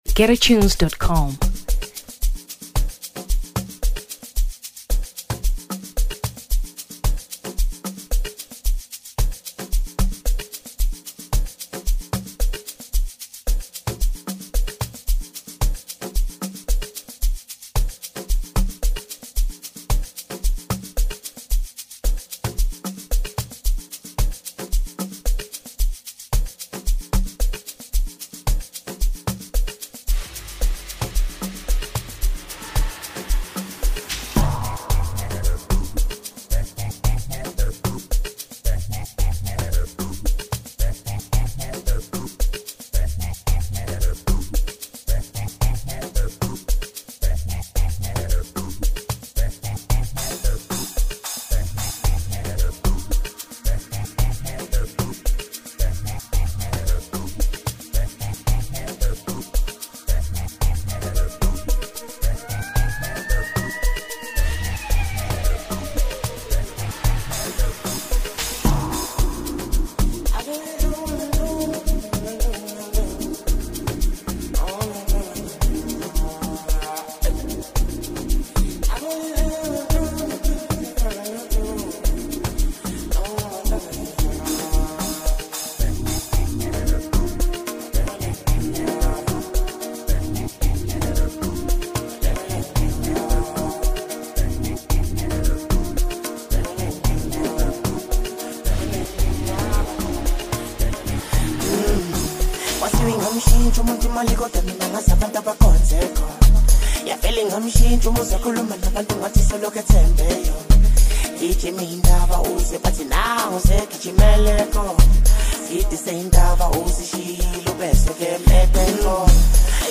Amapiano 2023 South Africa